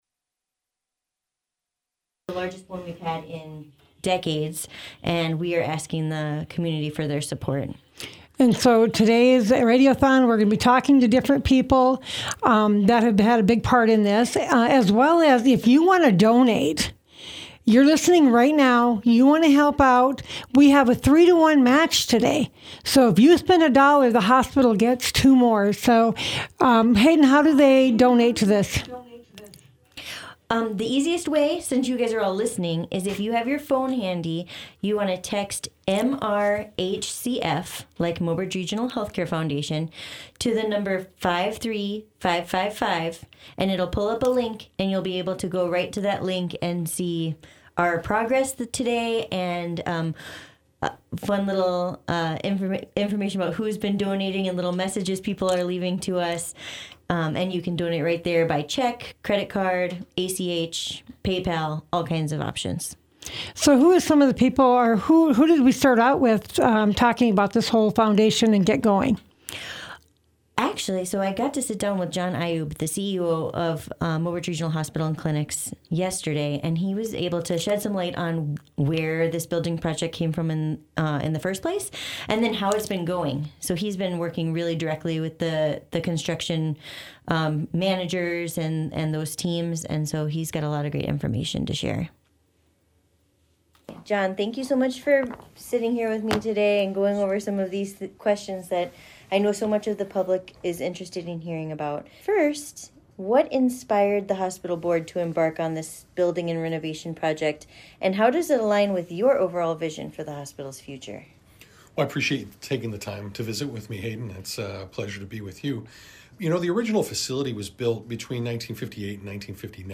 $25,000 donation from Bridgemark Insurance Solutions donated during radio fundraiser
DRG Media Group hosted a radio-a-thon to help raise money for the Mobridge Regional Healthcare Foundation Capitol Campaign today (Dec. 3, 2024).
radio-a-thon-dec-3-2024.mp3